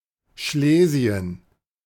The names of Silesia in different languages most likely share their etymology—Polish: Śląsk [ɕlɔ̃sk] ; German: Schlesien [ˈʃleːzi̯ən]
De-Schlesien.ogg.mp3